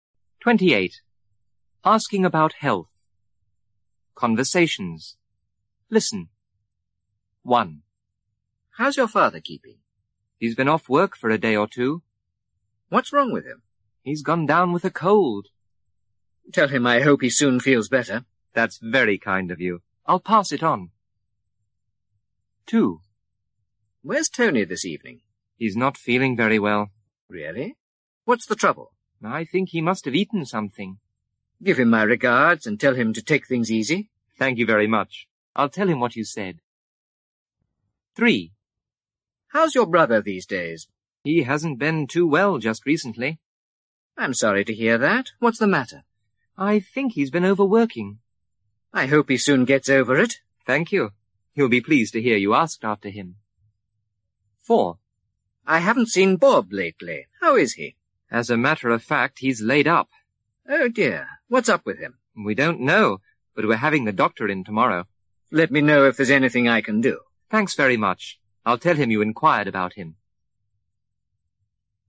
برای یادگیری هر چه بهتر این مکالمه، ترجمه فارسی به همراه فایل صوتی مکالمه مورد نظر را برای شما همراهان عزیز وبسایت کاردوآنلاین آماده کرده ایم.